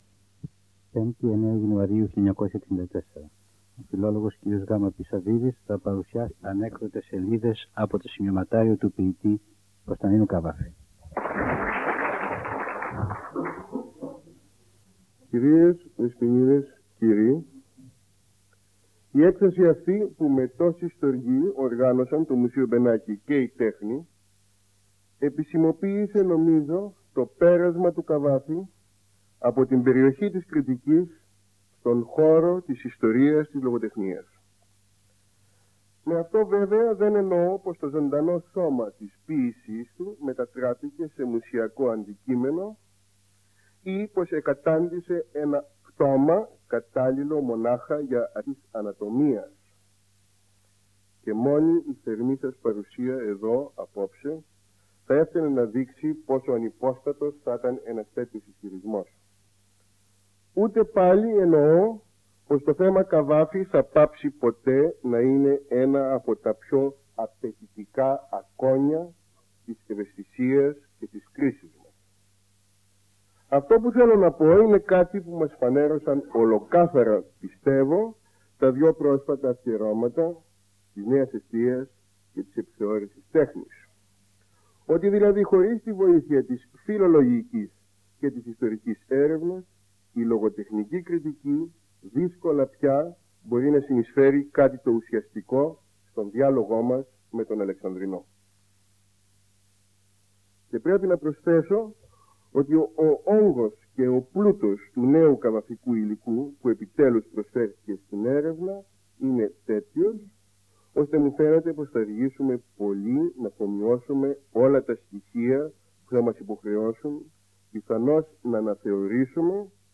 Εξειδίκευση τύπου : Εκδήλωση
Δημιουργός: Σαββίδης, Γιώργος Πάνου
Περιγραφή: Ομιλία